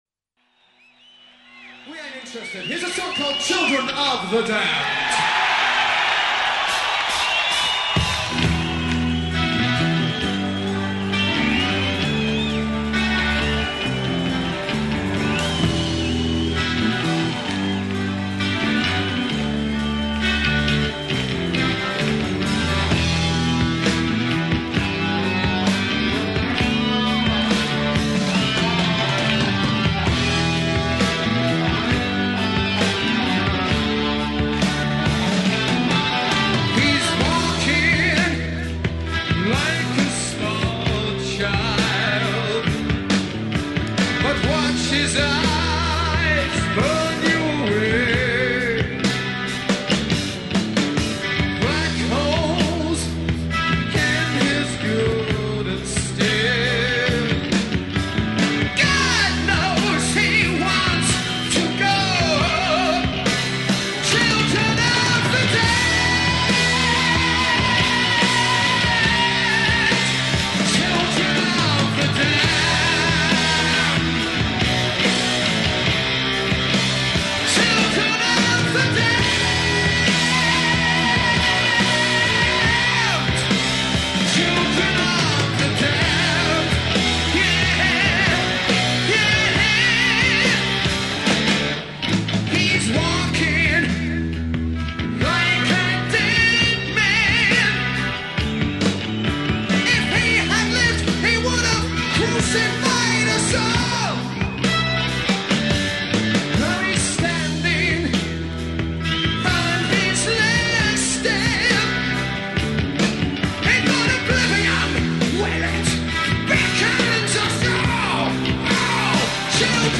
Live Concert NWOBHM